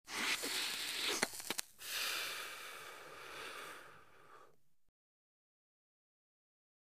Quick Inhale On Cigarette And Exhale